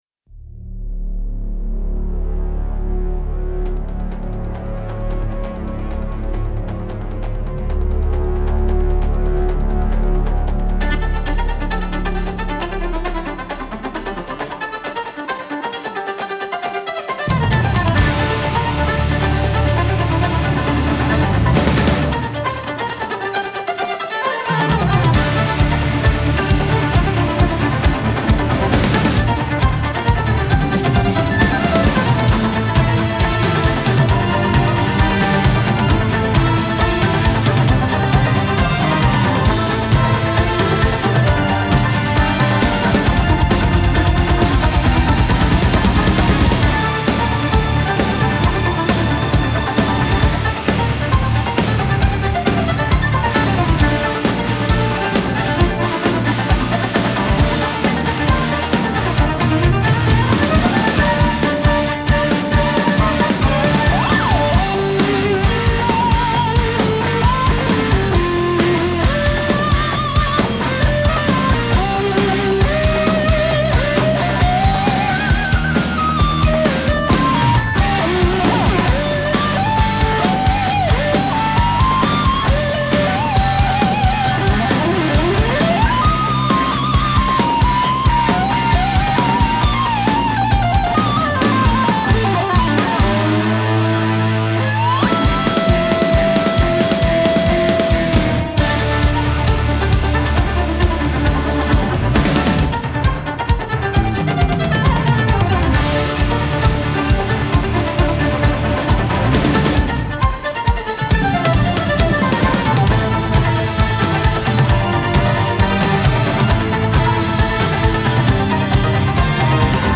Voicing: Violin